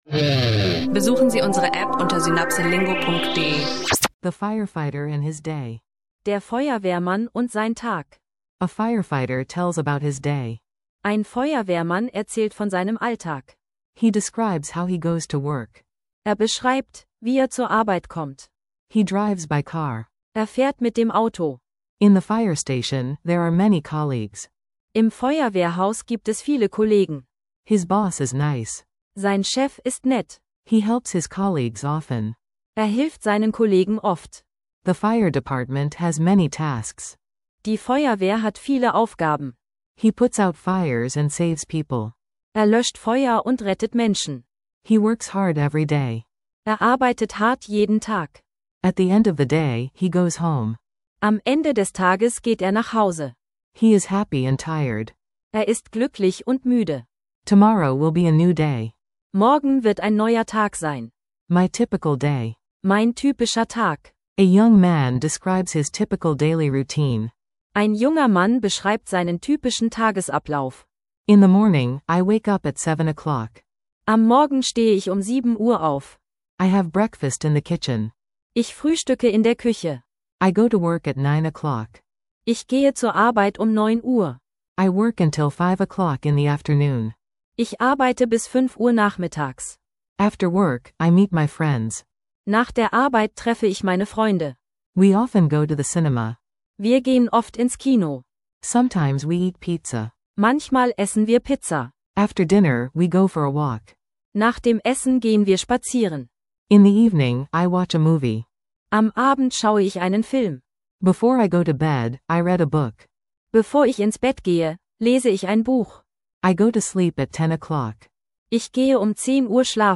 In dieser Episode von SynapseLingo lernst du, wie Englisch im Alltag gesprochen wird, während ein Feuerwehrmann und ein junger Mann ihren typischen Tag beschreiben.